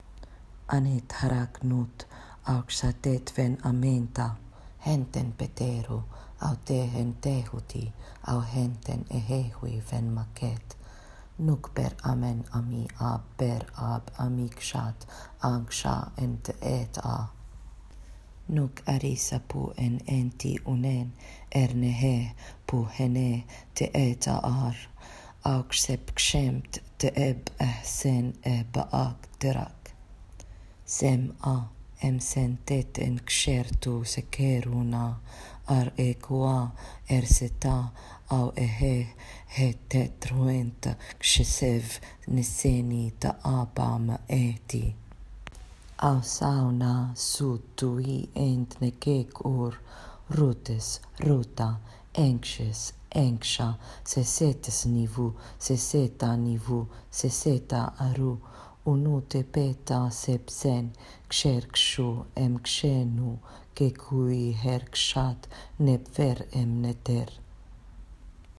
Reading of the prayer –